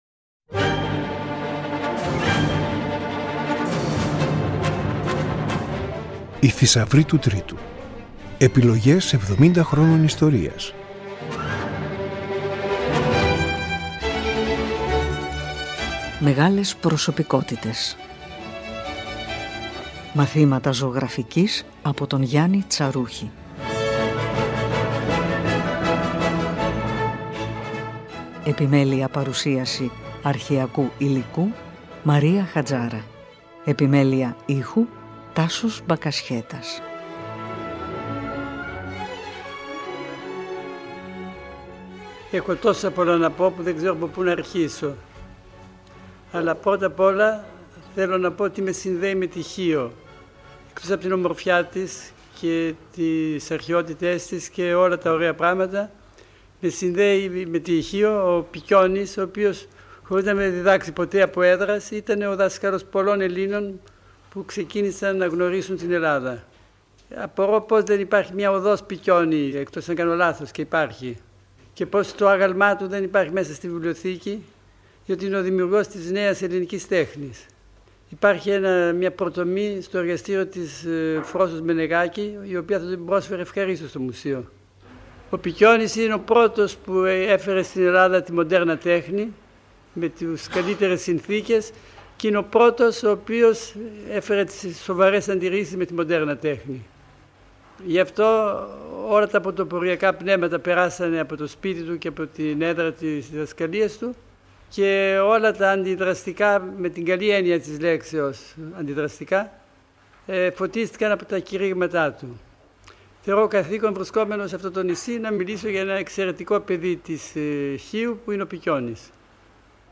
Πρόκειται για ένα μοναδικό ντοκουμέντο, μια σπάνια καταγραφή του λόγου του σε μια σειρά διαλέξεων στο Ιωνικό Κέντρο Επιστημονικών και Πνευματικών Σπουδών στη Χίο. Με πρωτοβουλία του Μάνου Χατζιδάκι, διευθυντή τότε του Τρίτου Προγράμματος, οι διαλέξεις ηχογραφούνται, και παραμένουν στο αρχείο του ραδιοφώνου – πολύτιμο, ανέκδοτο υλικό.